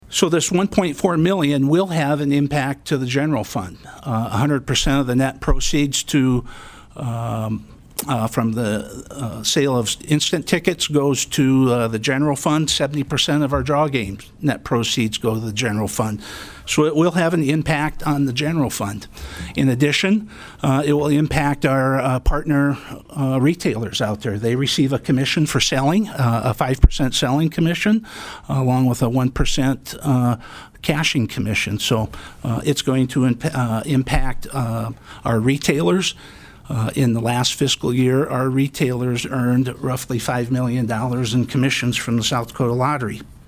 PIERRE, S.D.(HubCityRadio)- The South Dakota Senate Commerce & Energy Committee heard testimony on SB203 on Thursday.